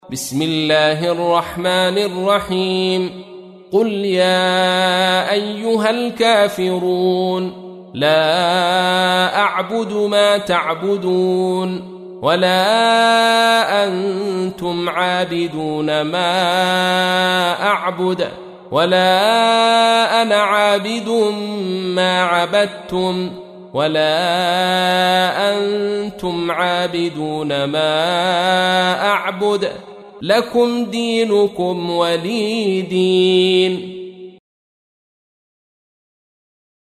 تحميل : 109. سورة الكافرون / القارئ عبد الرشيد صوفي / القرآن الكريم / موقع يا حسين